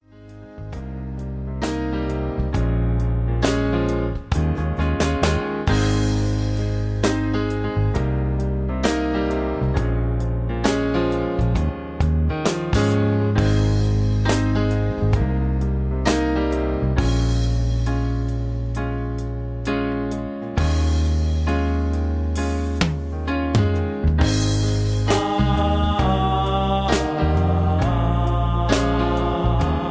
(Version-3, Key-Ab-A) Karaoke MP3 Backing Tracks
Just Plain & Simply GREAT MUSIC (No Lyrics).